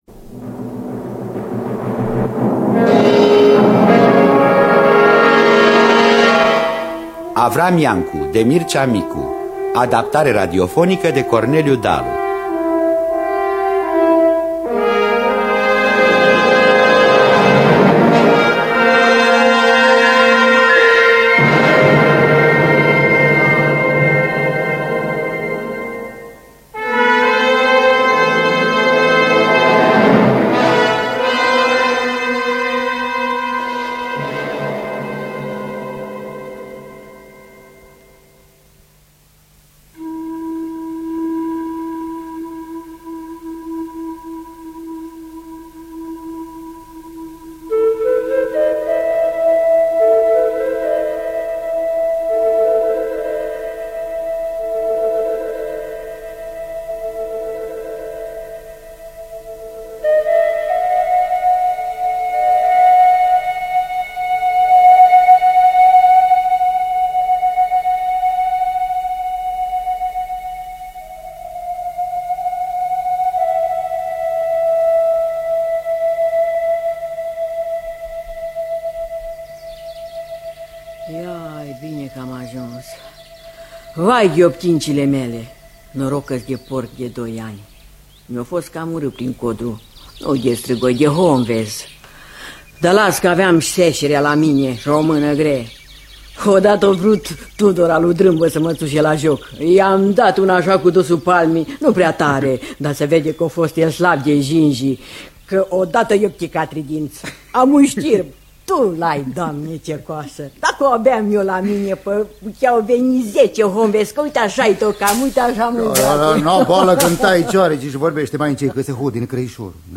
Adaptarea radiofonica